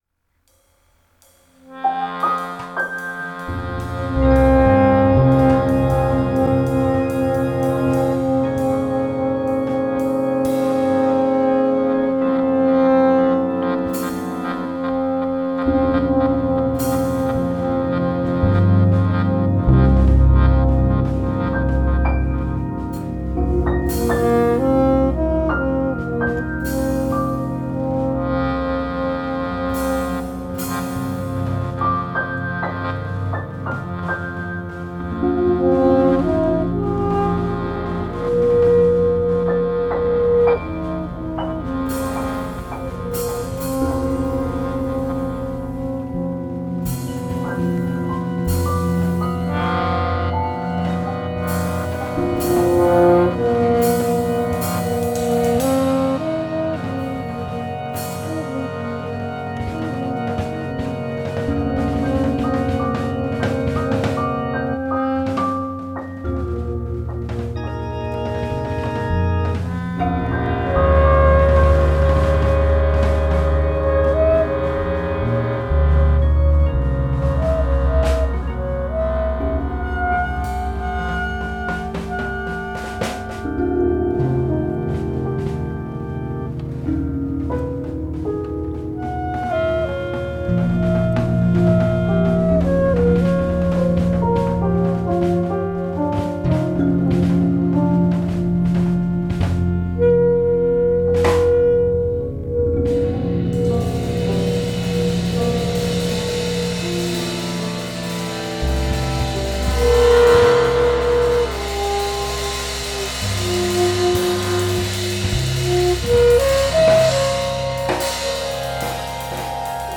with my organ playing on top